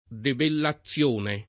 debellazione [ debella ZZL1 ne ] s. f.